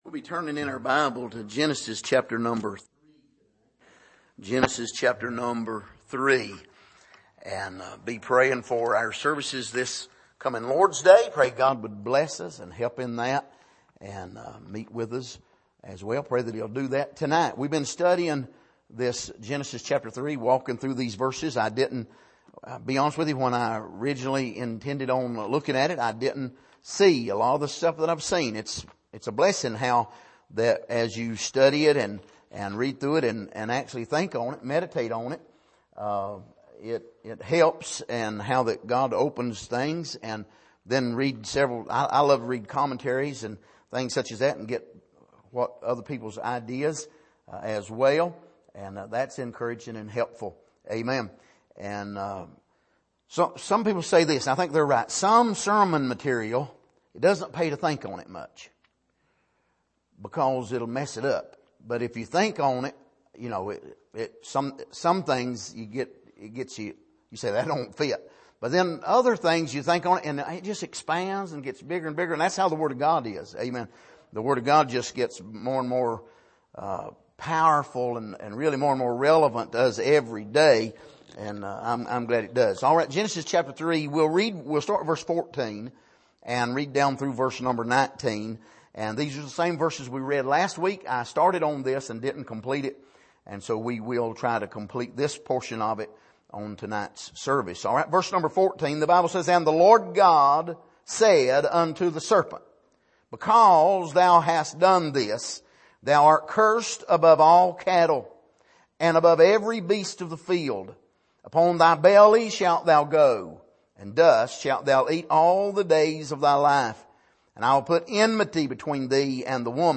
Passage: Genesis 3:14-19 Service: Midweek